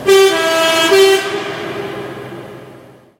Sons et bruitages gratuits de klaxons de trains
Klaxon TGV